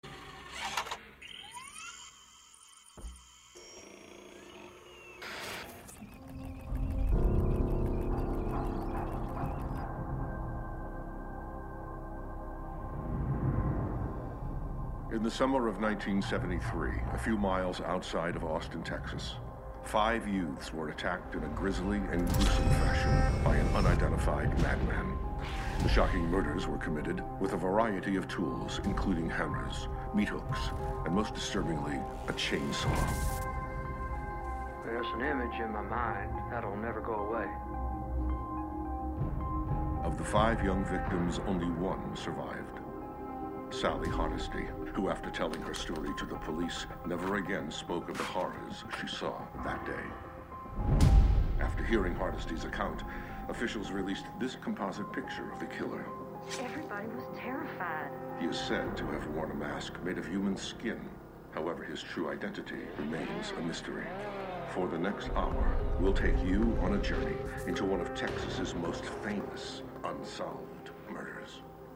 Opening Narration